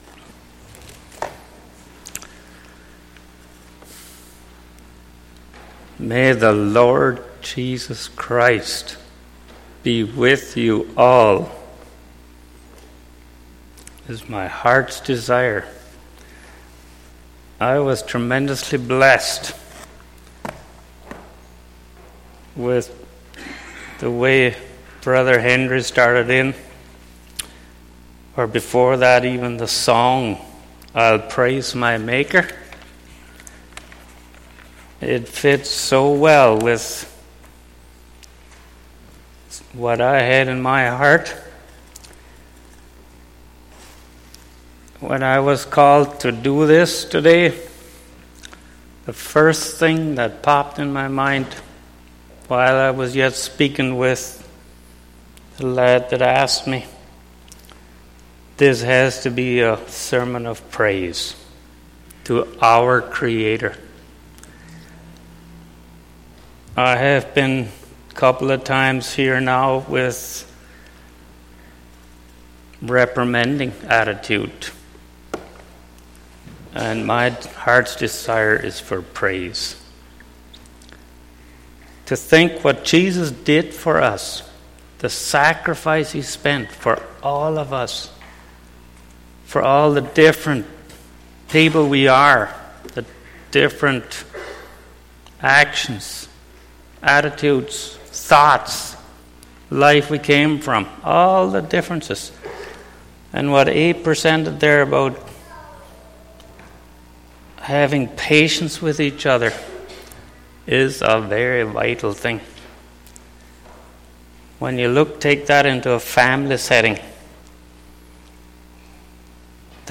Sunday Morning Sermon Service Type: Sunday Morning %todo_render% « Forbearing One Another in Love Condemnation?